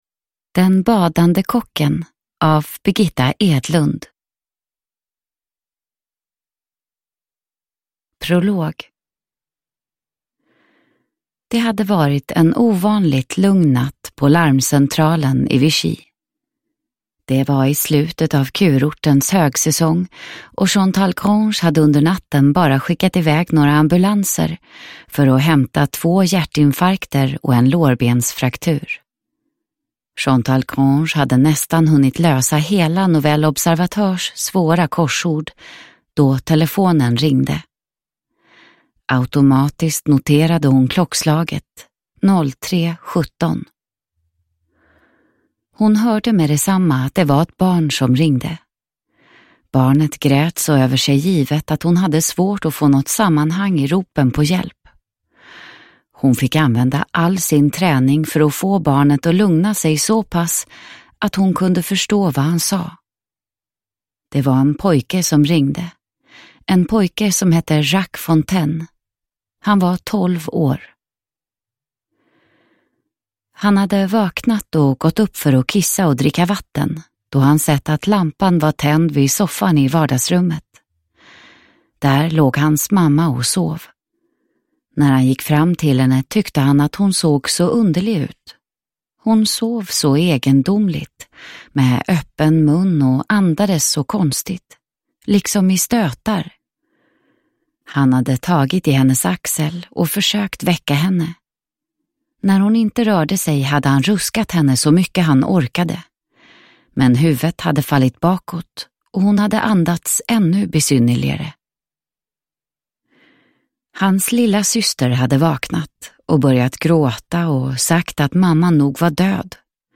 Den badande kocken – Ljudbok